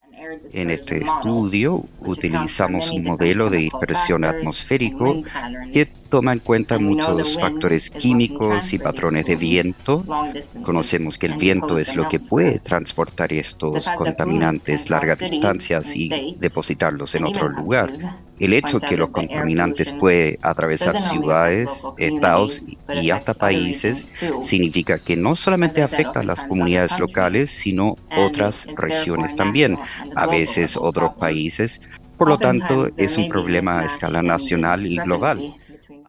entrevista-en-ingles-cuna-2.mp3